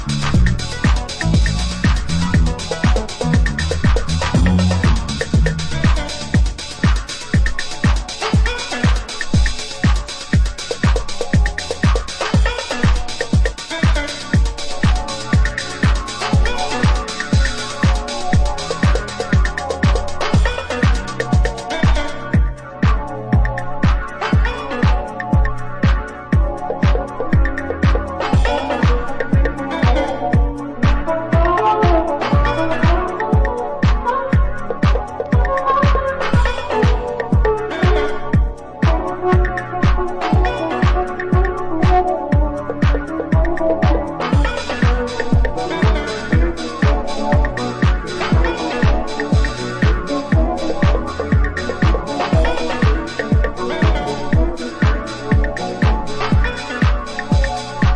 Chicago house at its best, deep and tracky, TIP!!!